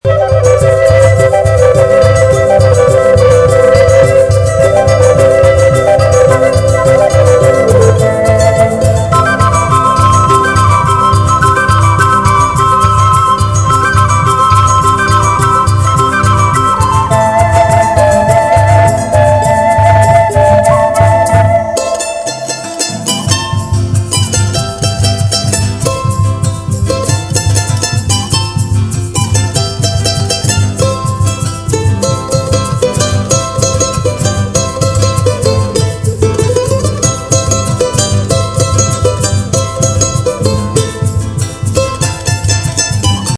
Carnavalito